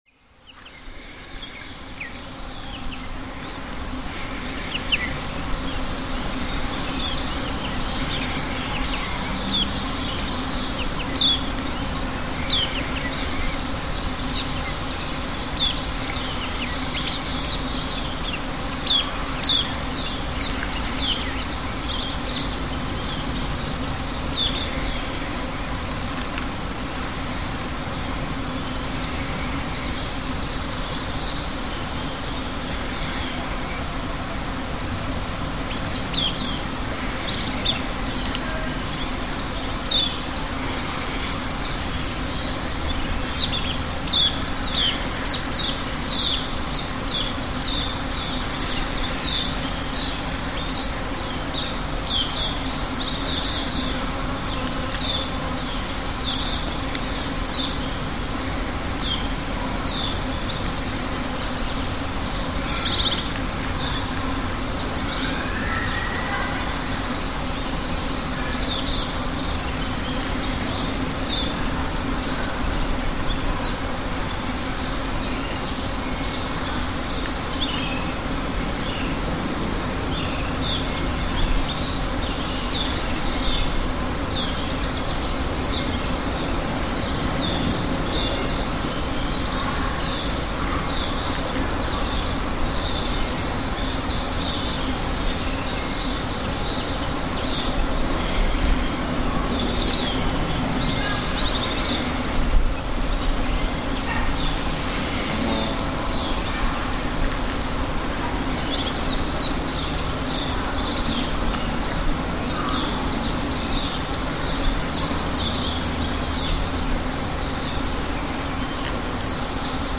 Field recording from Cape Town, South Africa.